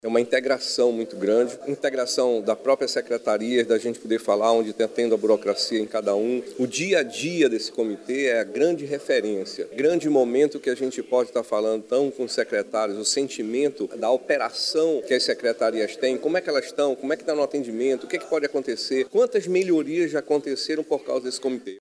A reunião ocorreu no Mirante Lúcia Almeida, localizado no Centro Histórico de Manaus.
SONORA-3-REUNIAO-ECONOMIA-MANAUS-.mp3